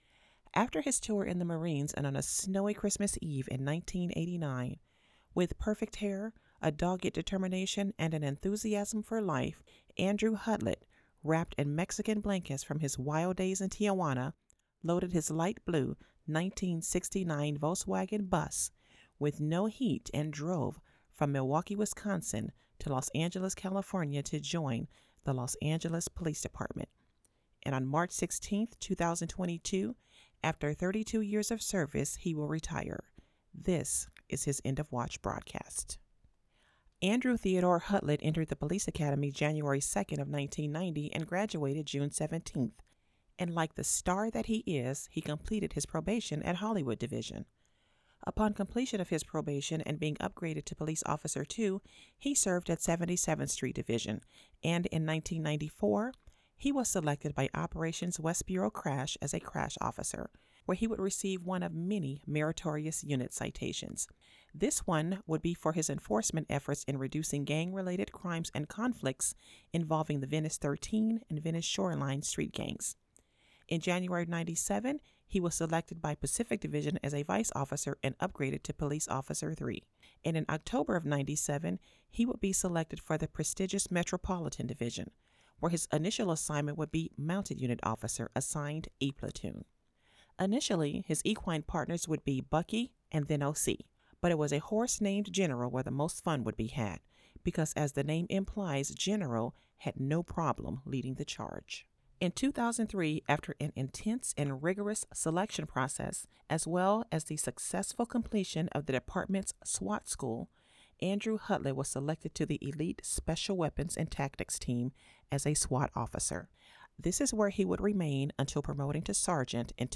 Female
My voice is authentic, smooth and can be raspy at times. It is professional, articulate, trustworthy, assertive, warm, inviting and believable. It's also quite soothing.
Studio Quality Sample
Natural Speak